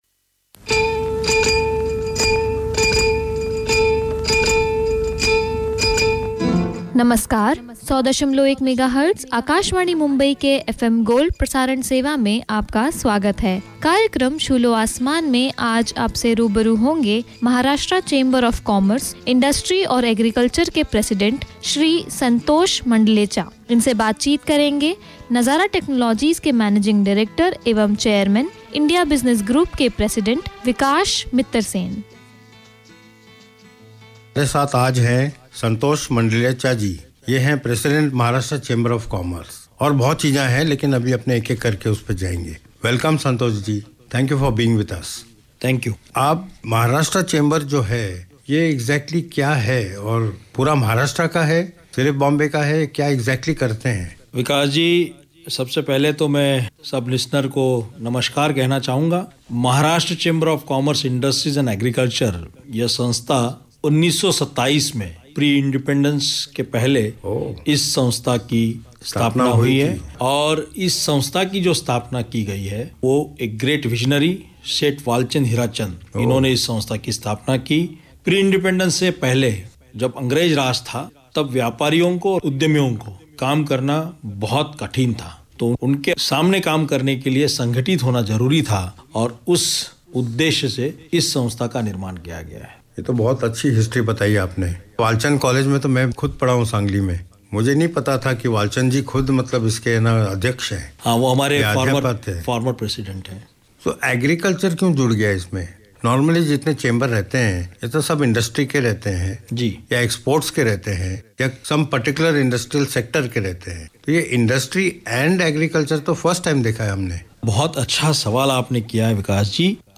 Weekly Radio Talk